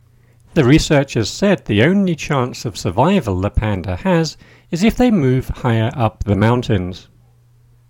DICTATION 8